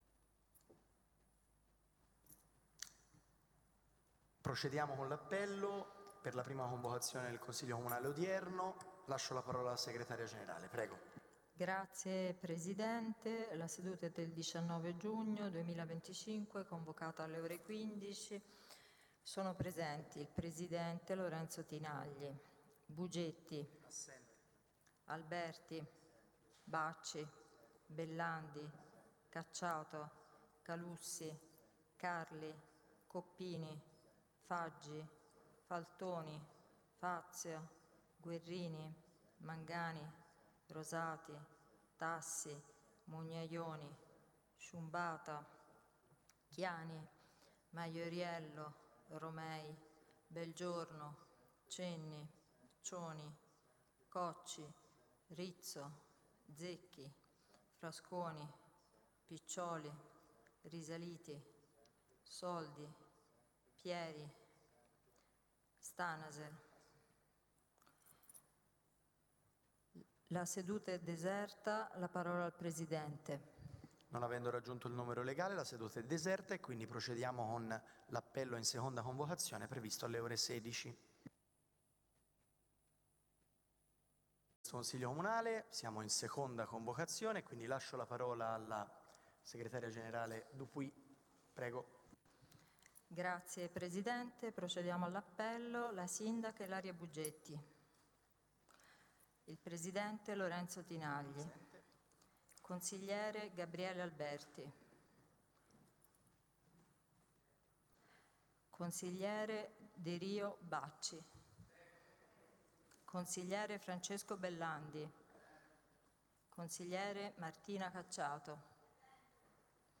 Registrazioni audio delle sedute del Consiglio Comunale di Prato.
Audio Consiglio Comunale del 19/06/2025